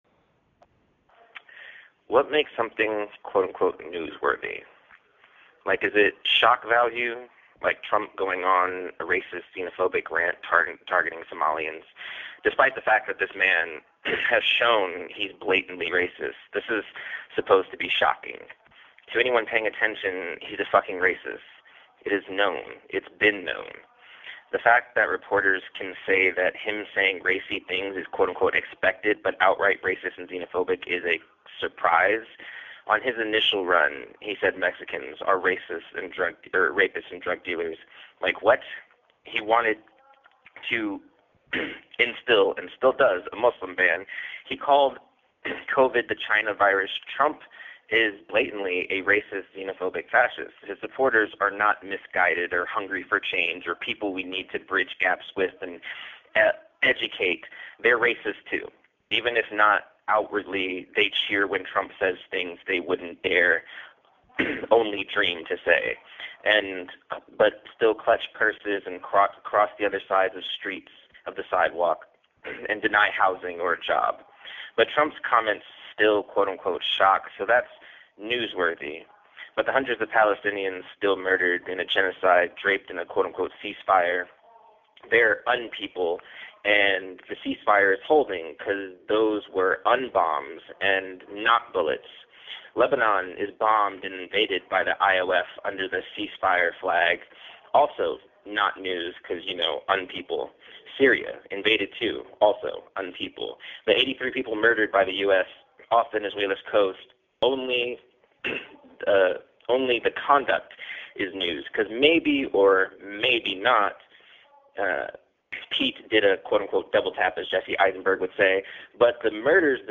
narrating his essay.